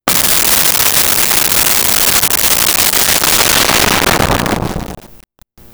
Explosion Distant Large 02
Explosion Distant Large 02.wav